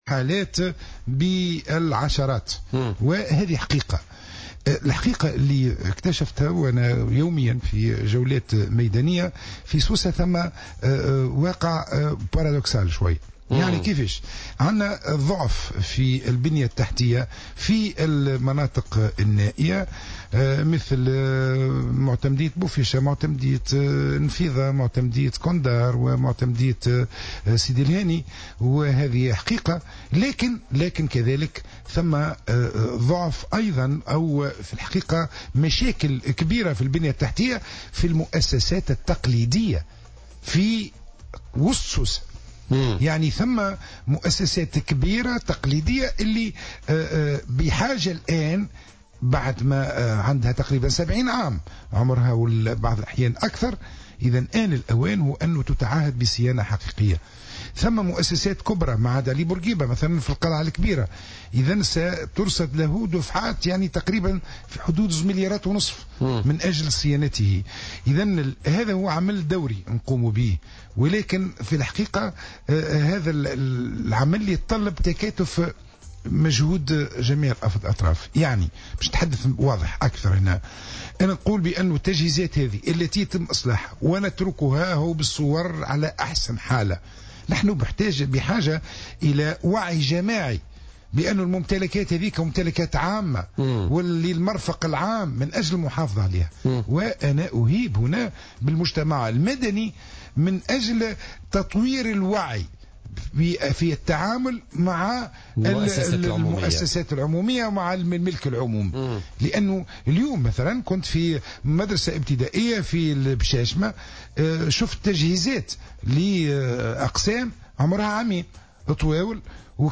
وأكد المندوب ضيف "بويليتكا" اليوم الخميس، الذي تولى مهامه منذ 3 أسابيع، ضرورة إجراء صيانة حقيقية لعدة مؤسسات مثل معهد علي بورقيبة في القلعة الكبرى الذي رصدت له ميزانية قدرت بحوالي مليارين ونصف لصيانته.